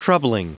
Prononciation du mot troubling en anglais (fichier audio)
Prononciation du mot : troubling